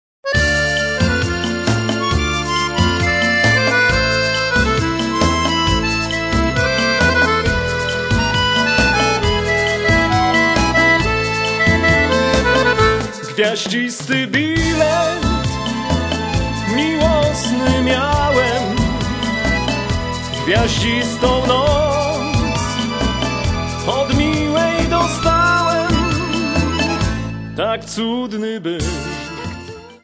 3 CD Set of Polish Gypsy Music.